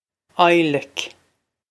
Adhlaic Eye-lik
This is an approximate phonetic pronunciation of the phrase.